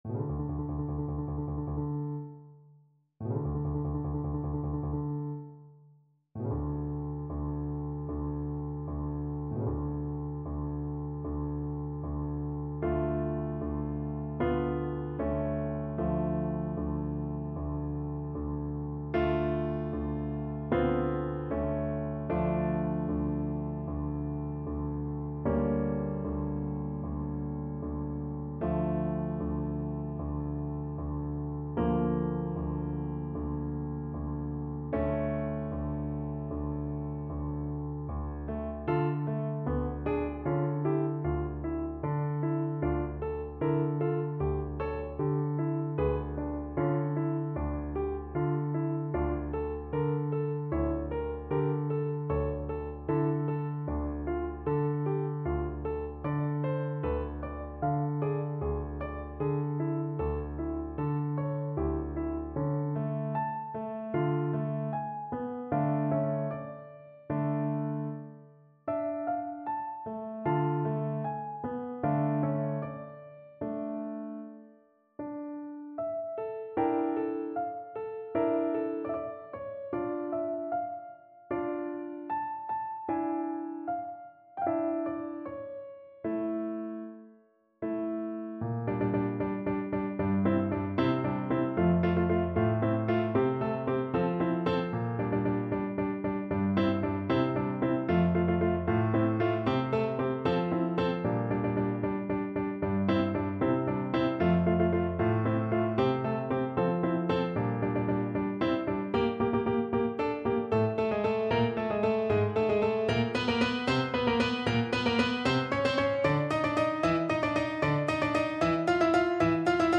Classical Tchaikovsky, Pyotr Ilyich Marche Slave French Horn version
French Horn
4/4 (View more 4/4 Music)
= 76 Moderato in modo di marcia funebre
D minor (Sounding Pitch) A minor (French Horn in F) (View more D minor Music for French Horn )
Classical (View more Classical French Horn Music)